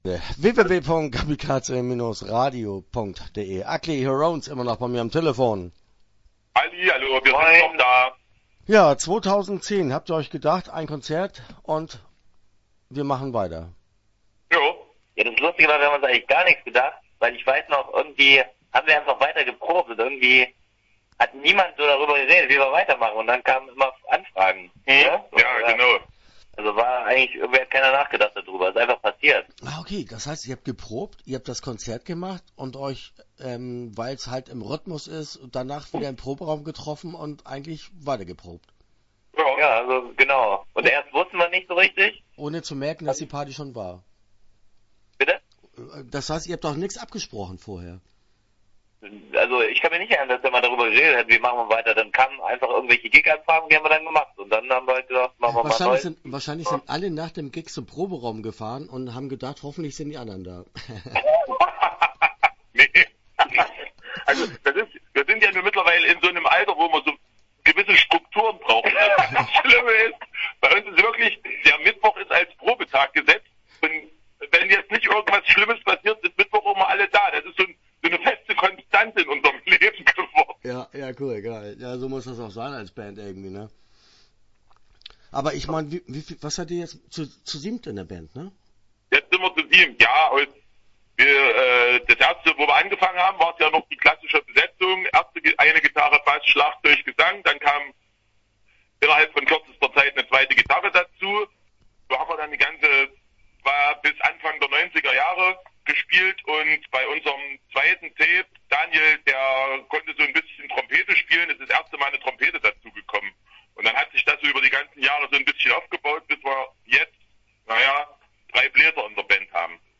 Ugly Hurons - Interview Teil 1 (12:22)